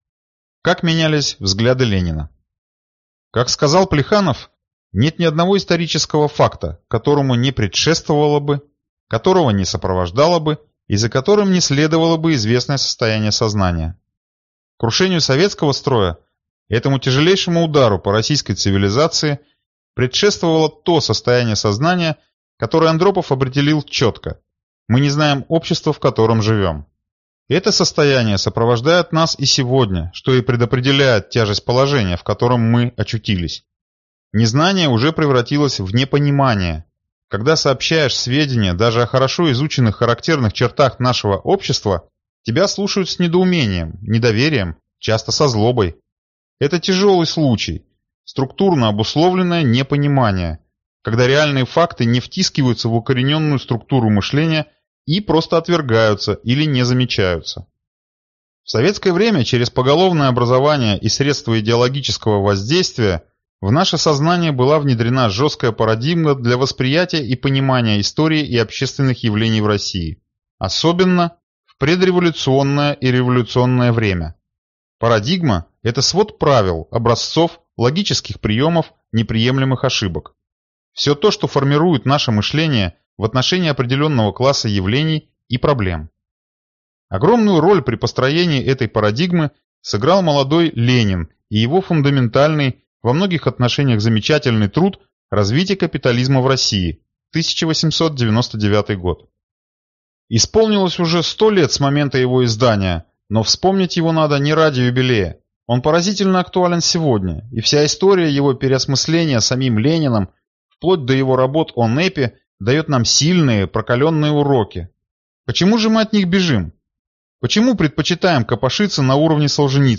Aудиокнига Советская цивилизация от начала до наших дней